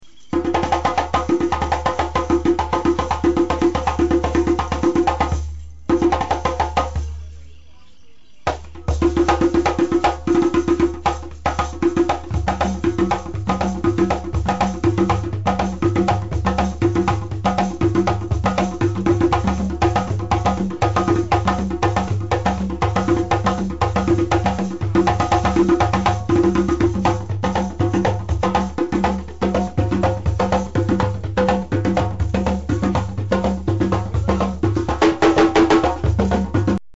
djembeDjembe
(pronounced / JEM-bay) also known as djimbe, jenbe, jembe, yembe, or sanbanyi in Susu; is a skin covered hand drum, shaped like a large goblet, and meant to be played with bare hands.
djembe.mp3